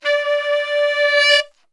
D 3 SAXSWL.wav